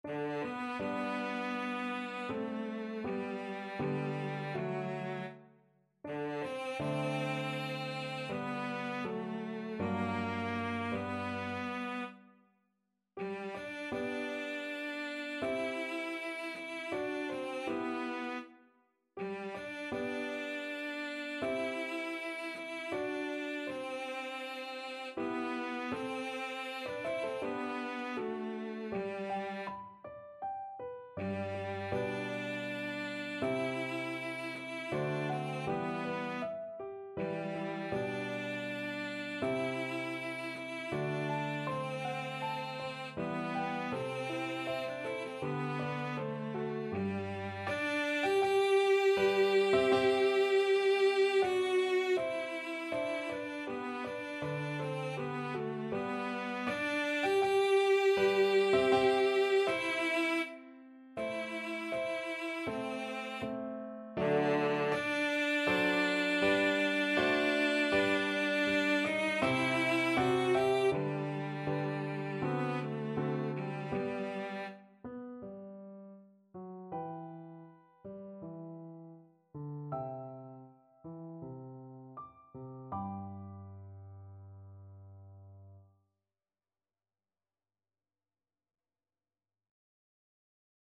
Cello version
Cello
G major (Sounding Pitch) (View more G major Music for Cello )
4/4 (View more 4/4 Music)
Andante
Classical (View more Classical Cello Music)